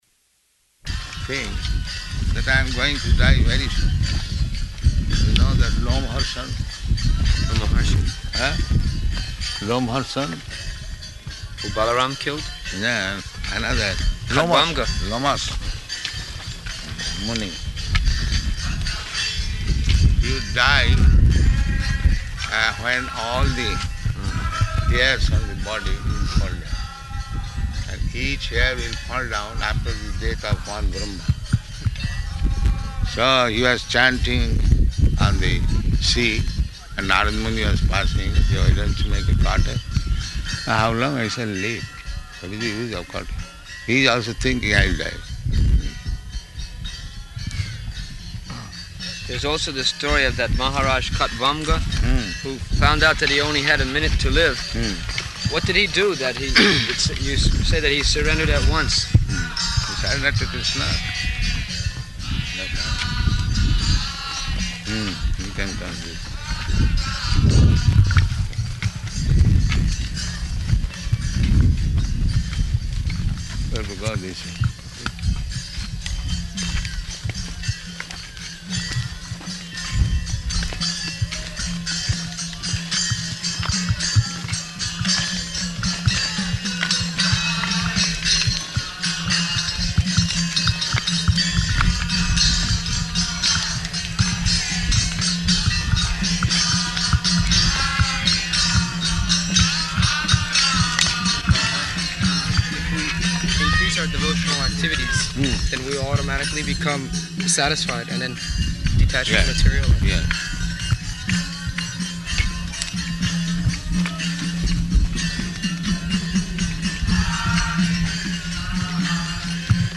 Type: Walk
Location: Māyāpur
[bad recording]